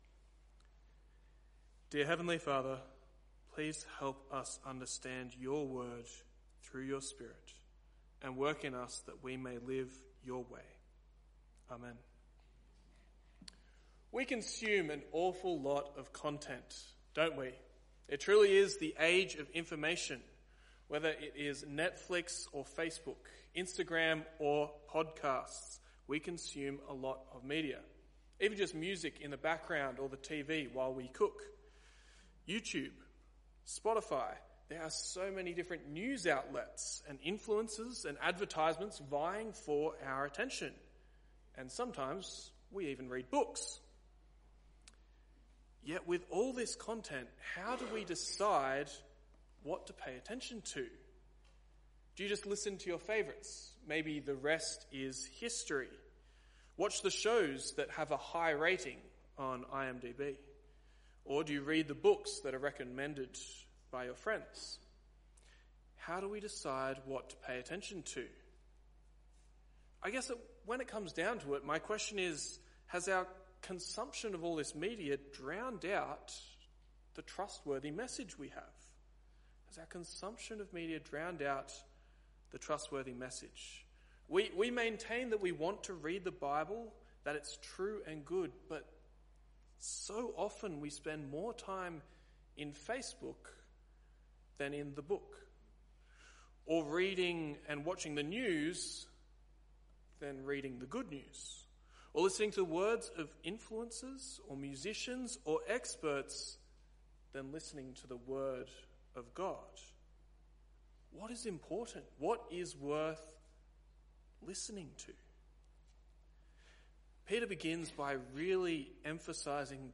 2 Peter | Sermon Books |